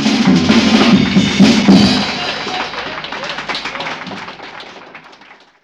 JAZZ BREAK 3.wav